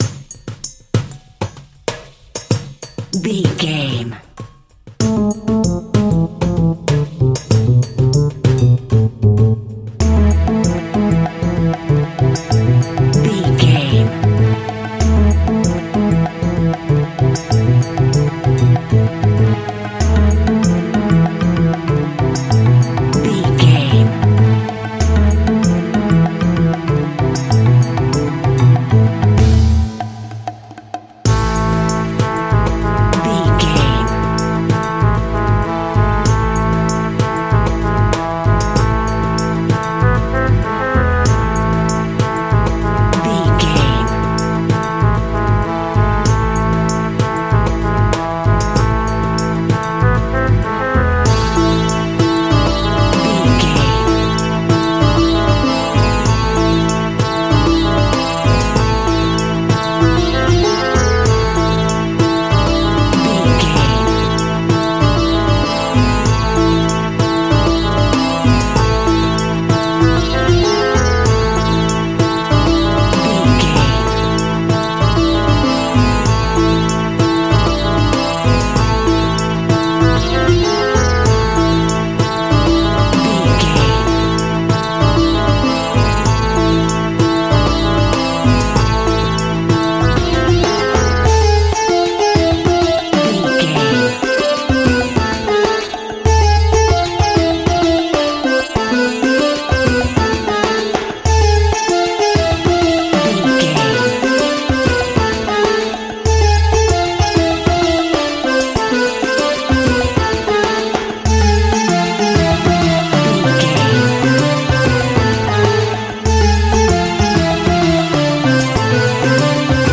Aeolian/Minor
Slow
sitar
bongos
sarod
tambura